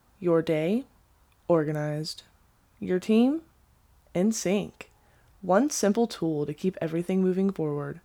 Professional Voiceover Artist
Clear, confident, and high-quality voiceovers for brands, content creators, and businesses.